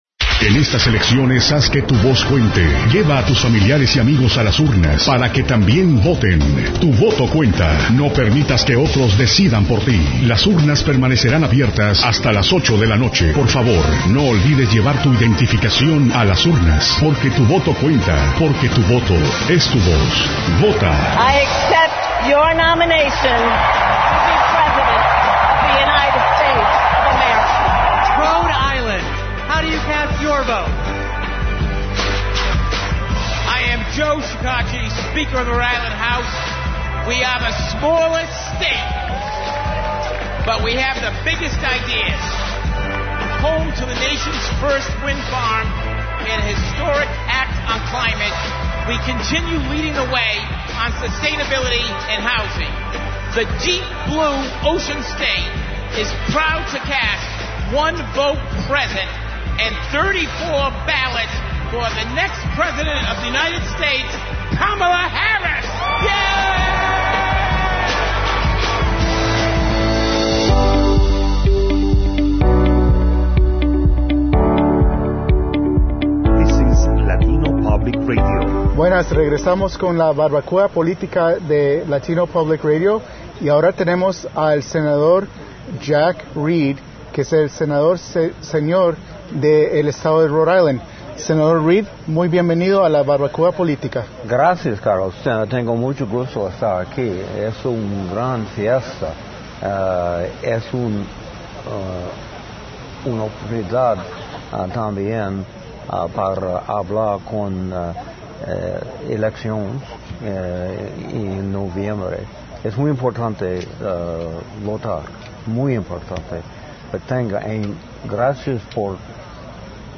In Spanish, Senator Jack Reed at LPR’s Political BBQ 2024
This interview was done in the Spanish language only